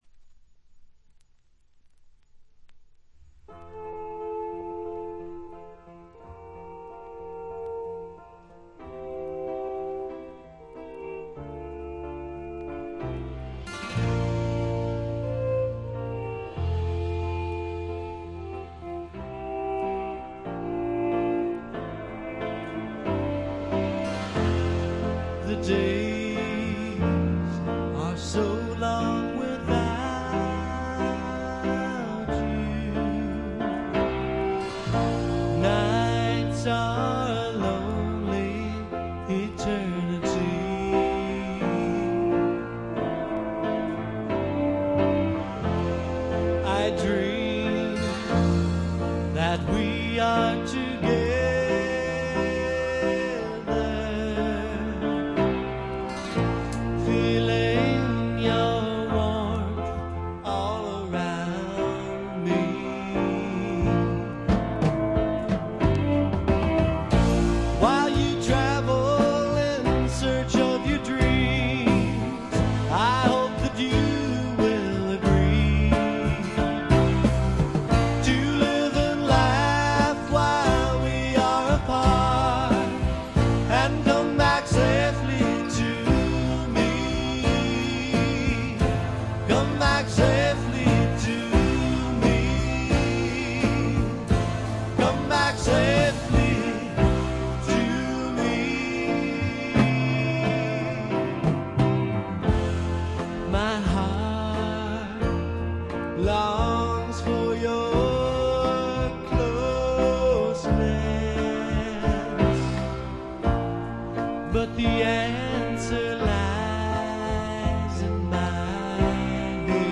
ほとんどノイズ感無し。
サンディエゴのシンガー・ソングライターによる自主制作盤。
試聴曲は現品からの取り込み音源です。